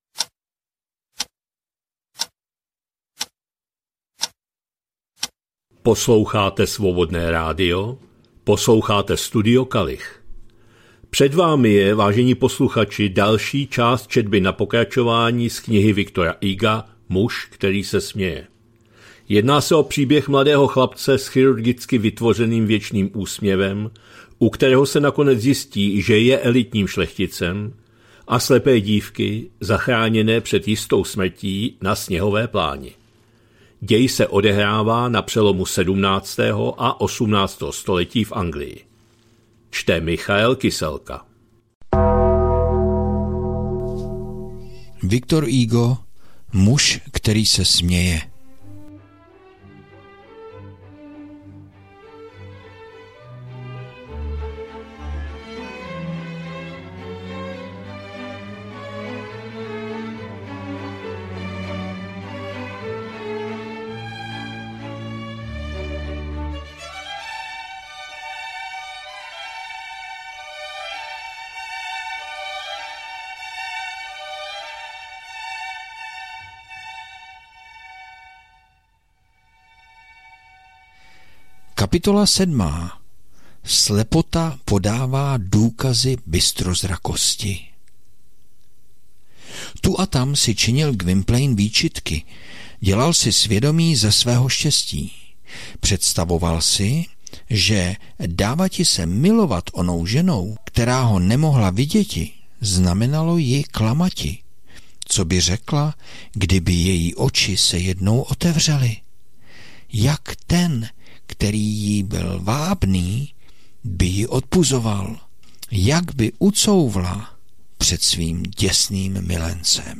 2025-09-29 - Studio Kalich - Muž který se směje, V. Hugo, část 28., četba na pokračování